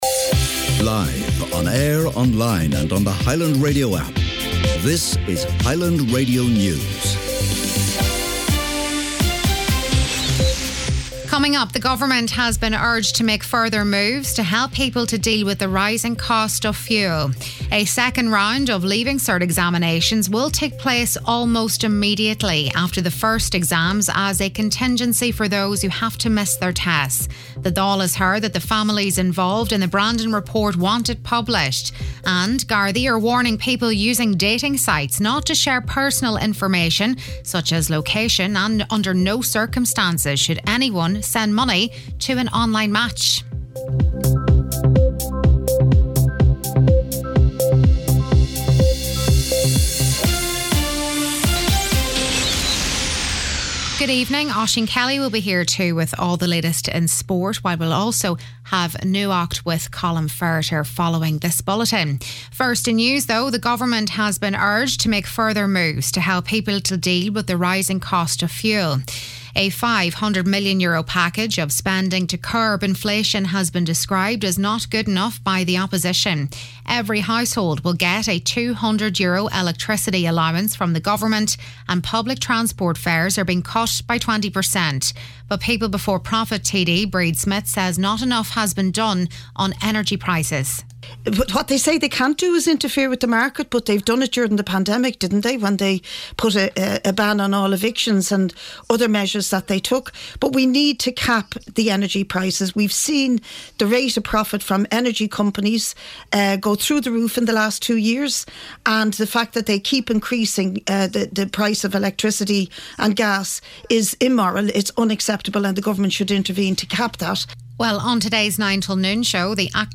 Listen back to main evening news, sport, nuacht & obituaries